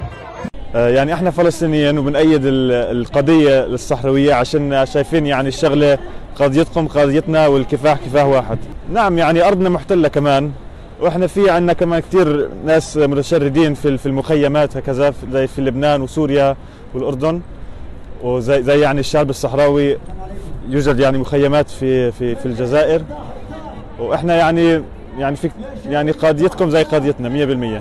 تظاهرة ببرلين للتنديد بذكرى الاجتياح المغربي للصحراء الغربية
مشارك فلسطيني عن حركة التضامن مع الشعب الصحراوي